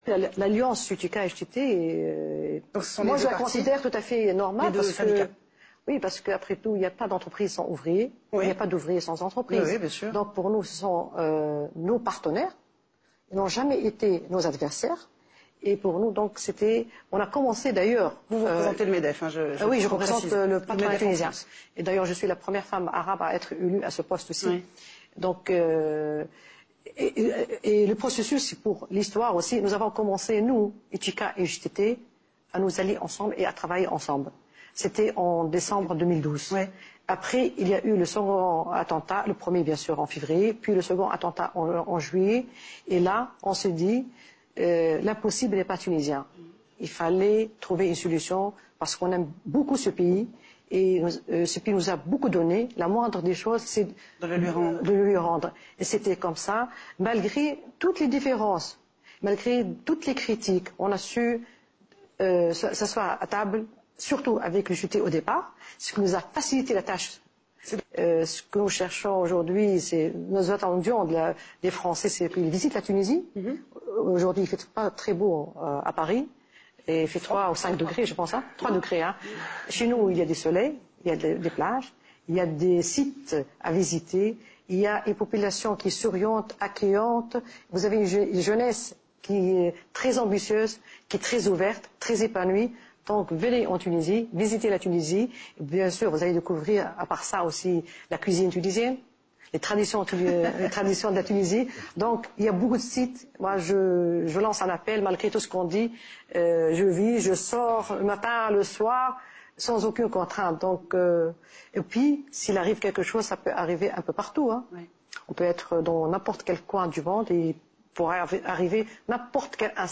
حلّت رئيسة اتحاد الصناعة والتجارة والصناعات التقليدية وداد بوشماوي ضيفة في البرنامج الحواري Le grand journal الذي يتابعه ملايين المشاهدين في العالم، أكّدت خلاله أنّ المنظمة الشغيّلة ومنظمة الأعراف شريكان وليسا خصميْن، مضيفة أن علاقة الشراكة بينهما ساهمت في استقرار البلاد.